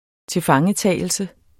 Udtale [ -ˌtæˀjəlsə ]